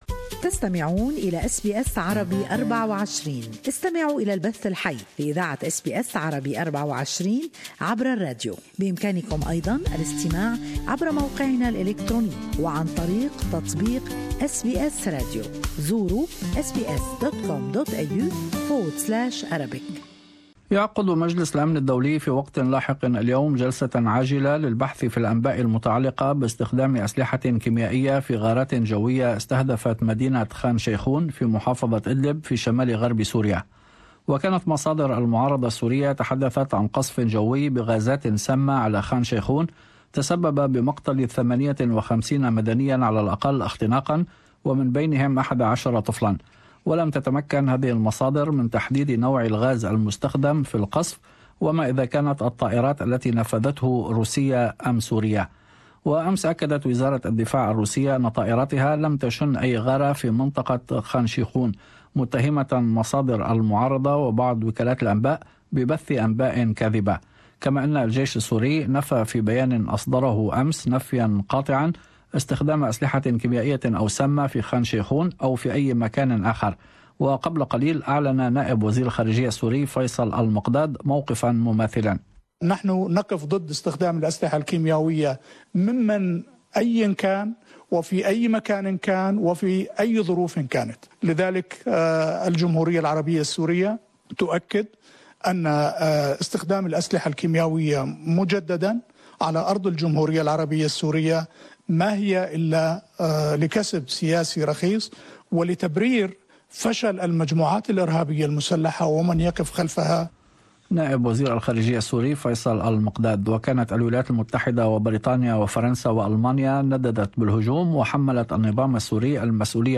News Bulletin Share